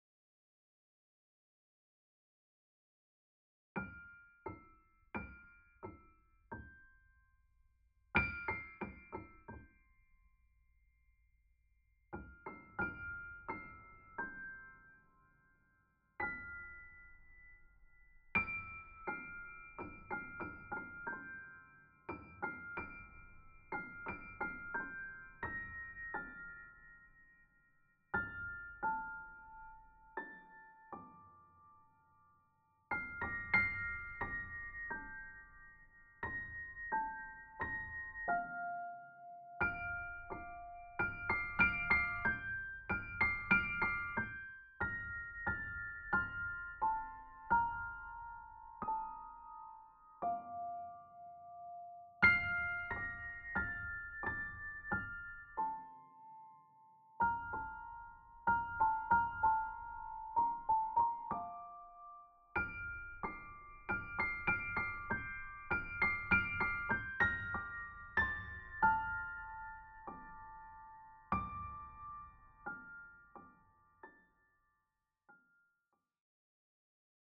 Over this week, I’ve been spontanously listening to some piano work, most of them are based on happiness and love.